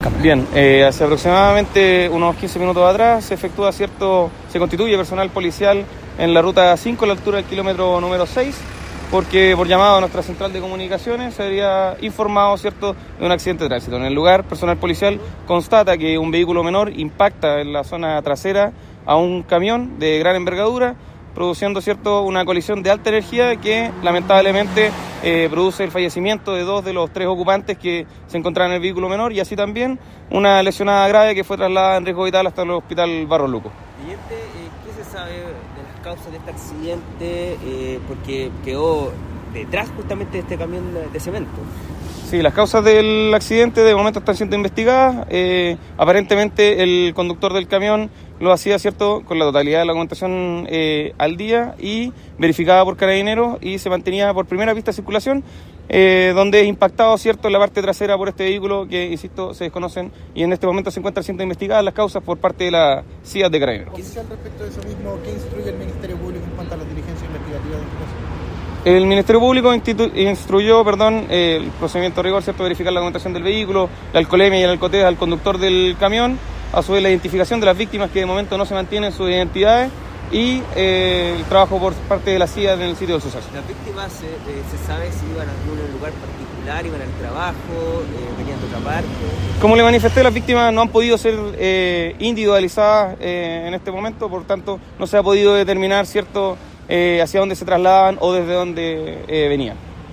Revisa a continuación la declaración completa:
Declaracion-accidente-ruta-5.mp3